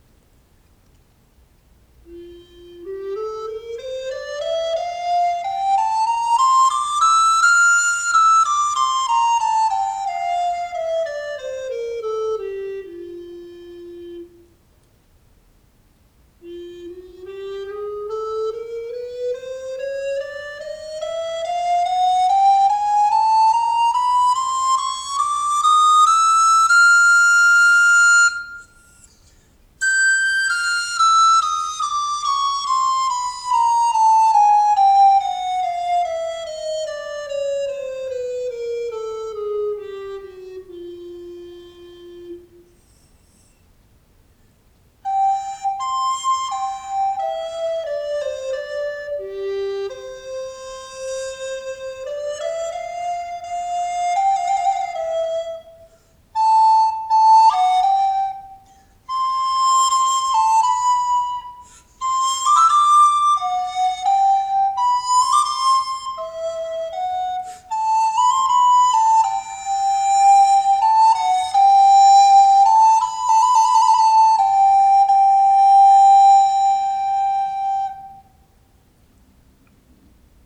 【材質】ペアウッド
ペアウッドも比重が小さいですが、チェリーよりは少し重い材。メープルに比べて、音色に粘りがあるように感じます。この個体は息の抵抗が大きく、軽い材にしては重厚な音色。少ない息でスイートスポット※に達せるので、息の量に自信のない方にもおすすめできます。